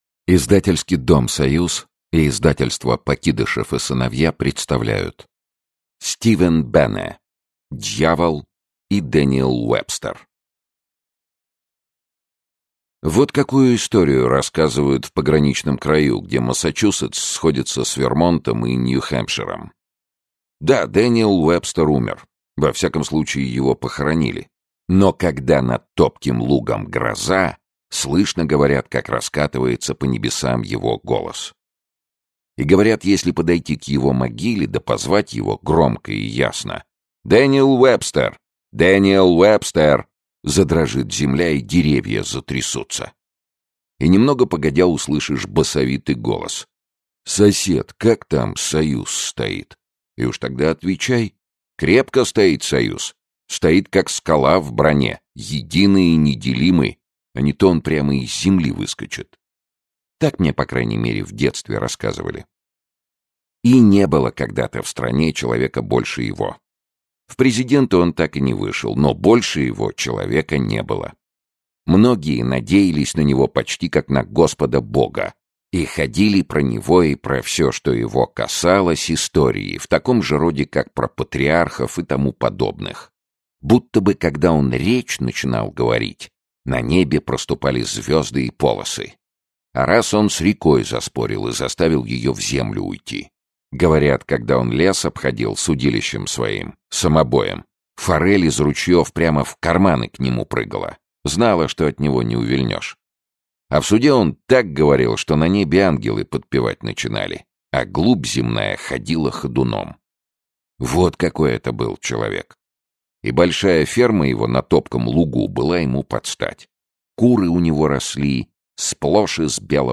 Аудиокнига Дьявол и Дэниэл Уэбстер и другие рассказы | Библиотека аудиокниг
Aудиокнига Дьявол и Дэниэл Уэбстер и другие рассказы Автор Стивен Бене Читает аудиокнигу Сергей Чонишвили.